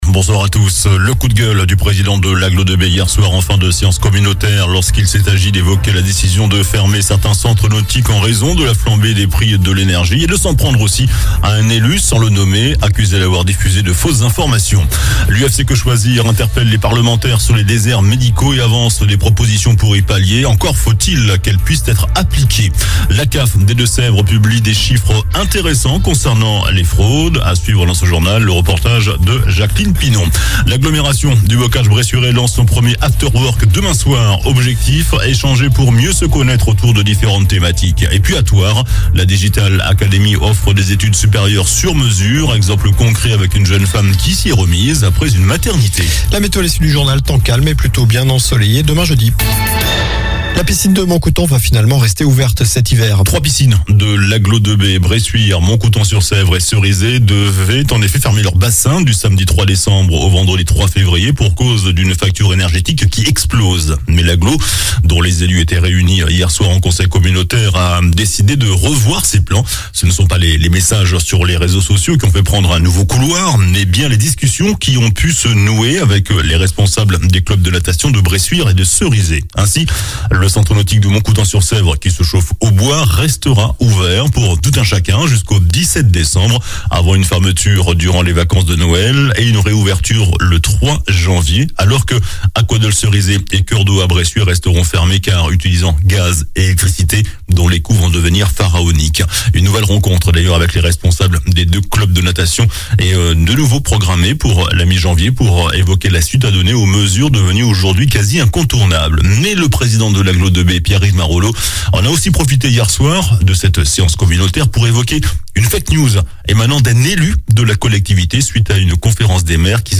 JORNAL DU MERCREDI 09 NOVEMBRE ( SOIR )